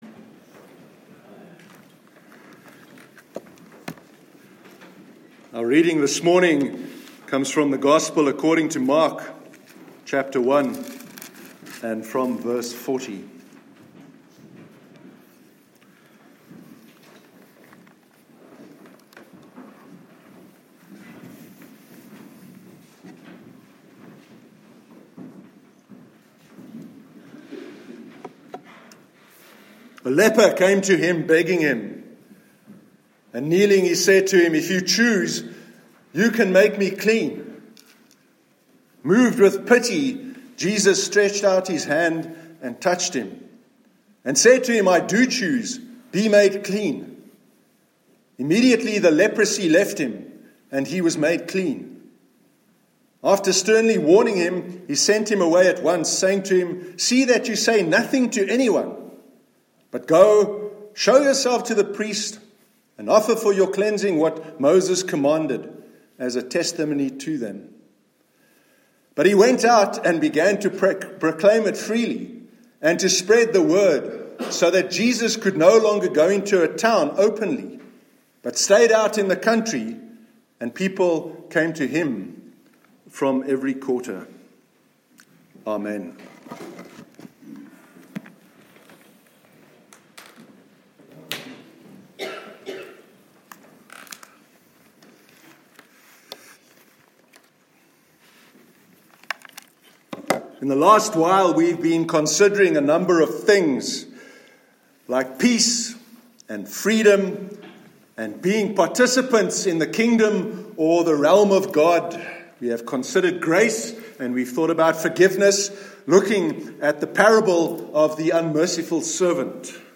Sermon on Joy- 25th February 2018